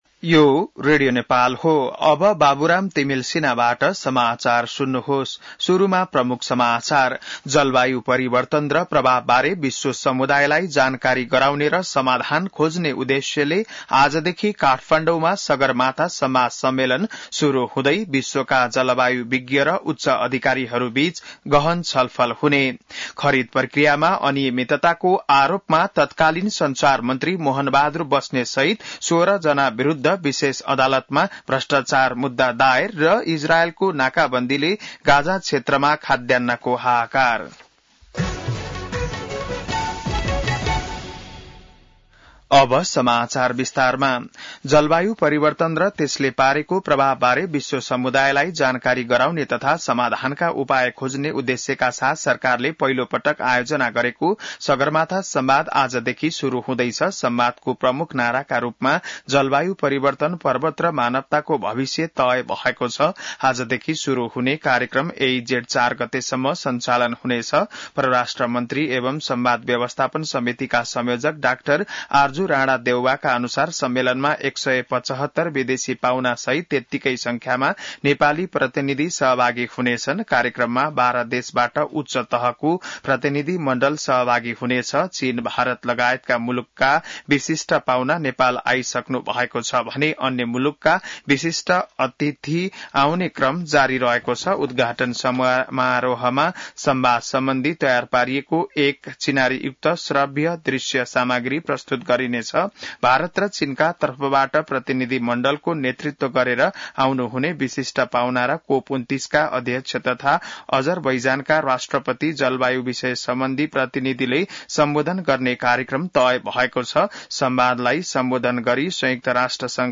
बिहान ९ बजेको नेपाली समाचार : २ जेठ , २०८२